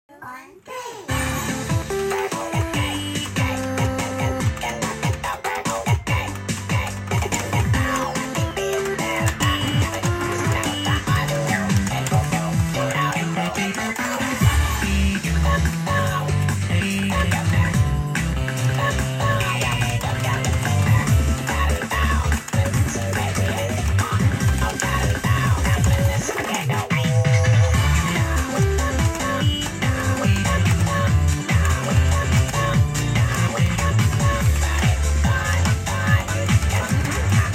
Sorry it was hard playing with one hand